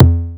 VEC3 Percussion 067.wav